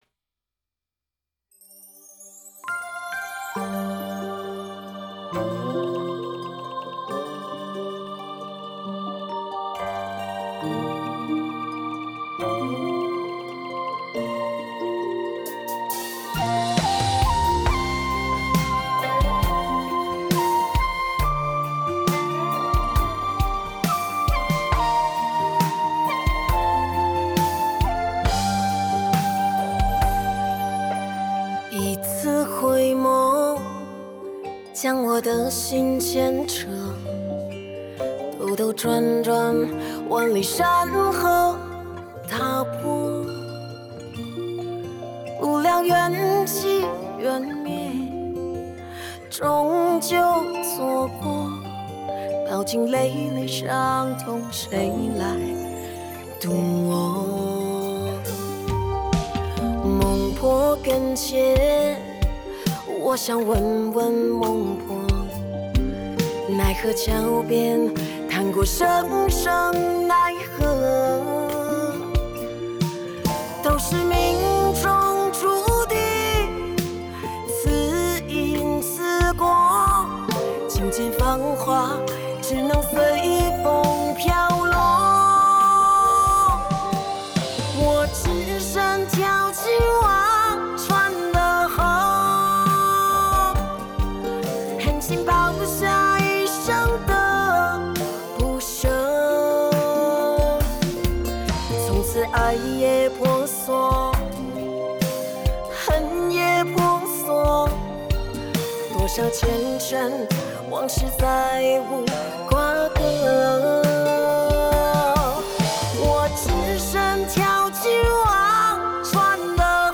吉他